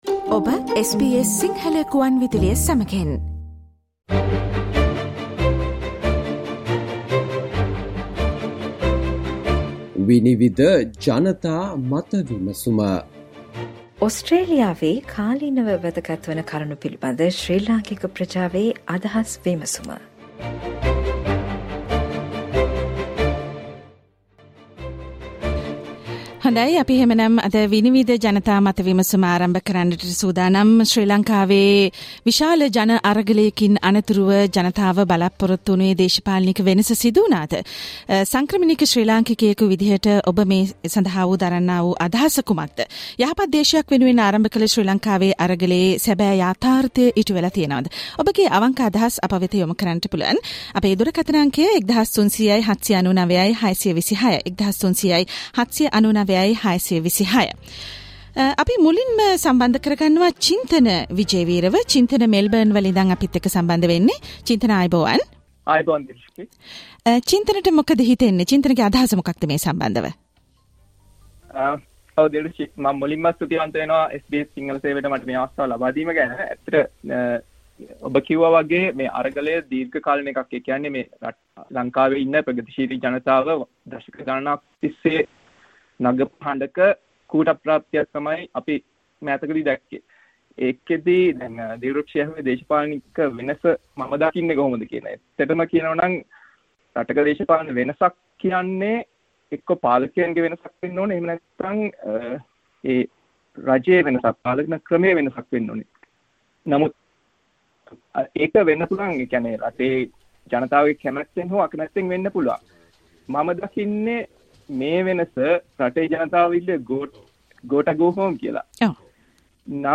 දුරකථනය ඔස්සේ සජීවීව අද අප සමඟ සම්බන්දවුයේ,